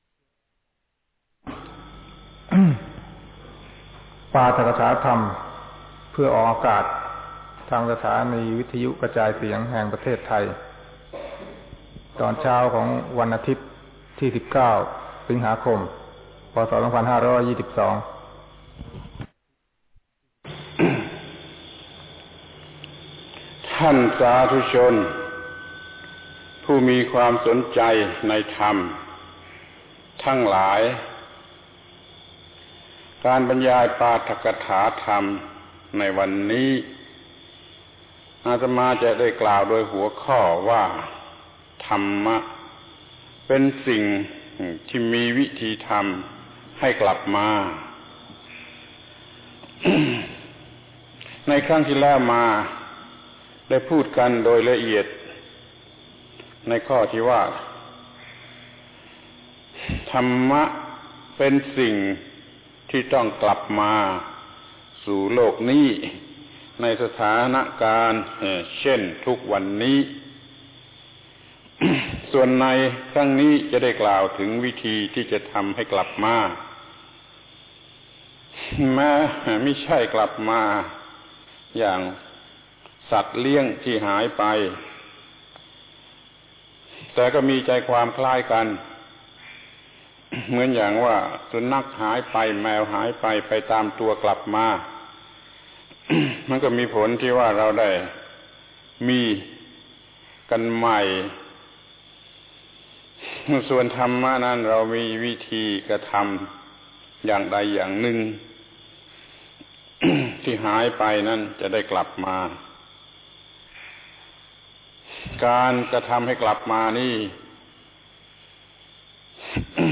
ปาฐกถาธรรมออกอากาศวิทยุประเทศไทย ศีลธรรมกลับมา ครั้งที่ 14 ธรรมะเป็นสิ่งที่มีวิธีทำให้กลับมา